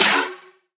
• Piercing Trap Snare Drum Sample C# Key 98.wav
Royality free steel snare drum sample tuned to the C# note.
piercing-trap-snare-drum-sample-c-sharp-key-98-TR5.wav